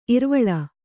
♪ irvaḷa